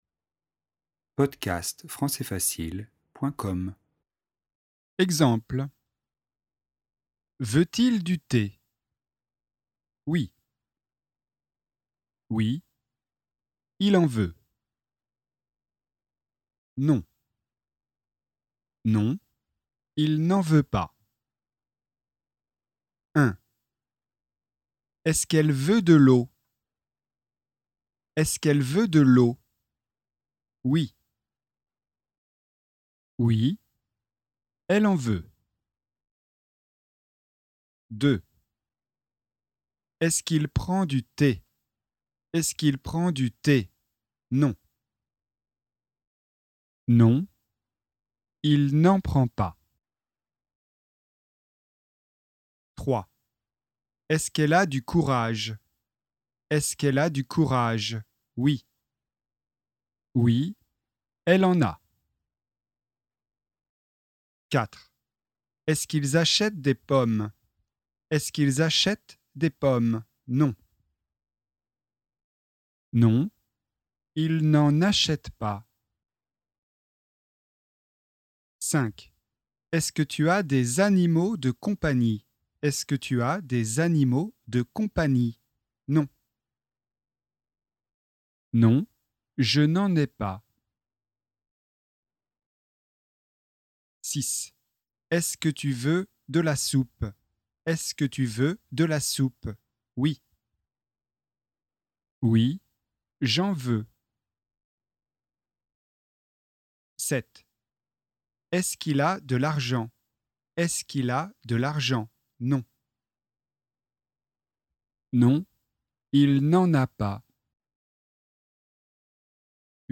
Exercice audio avec réponses :